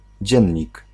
Ääntäminen
Synonyymit blad courant Ääntäminen Tuntematon aksentti: IPA: /daɣ.blat/ Haettu sana löytyi näillä lähdekielillä: hollanti Käännös Ääninäyte 1. dziennik {m} 2. gazeta {f} Suku: n .